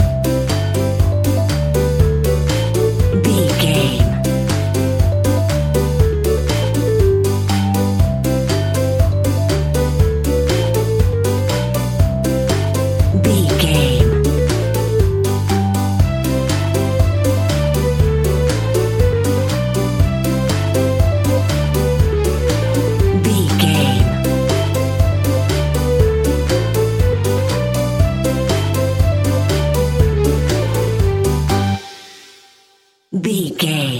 Uplifting
Ionian/Major
Fast
instrumentals
fun
childlike
happy
kids piano